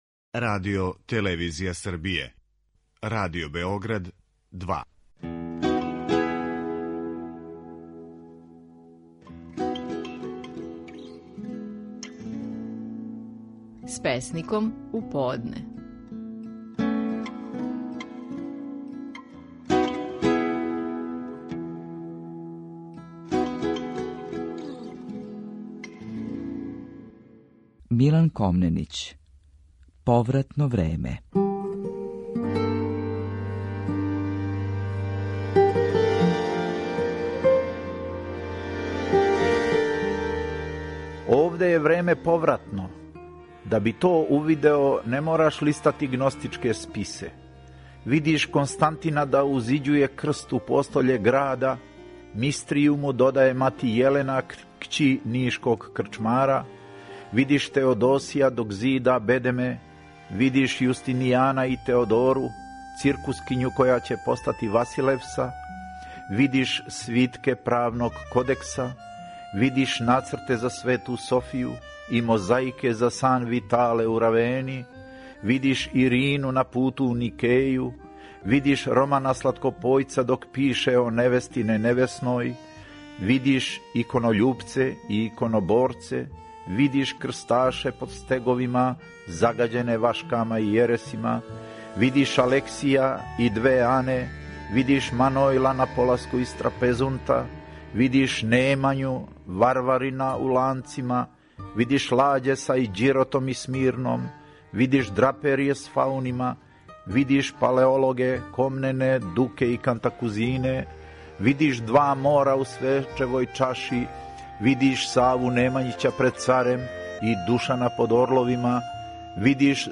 Стихови наших најпознатијих песника, у интерпретацији аутора.
Милан Комненић данас говори своју песму „Повратно време".